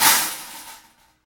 Index of /90_sSampleCDs/Roland - Rhythm Section/PRC_Trash+Kitch/PRC_Trash menu